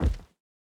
added stepping sounds
Rubber_01.wav